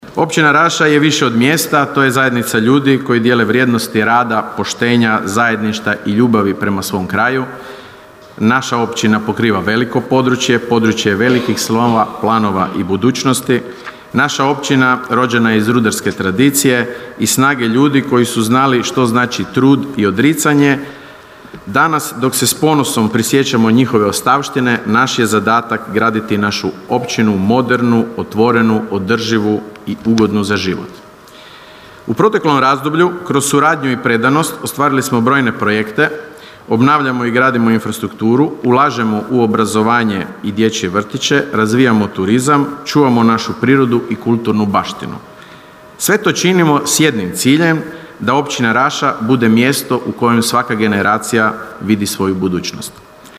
Svečanom sjednicom Općinskog vijeća Općina Raša jučer je proslavila svoj dan.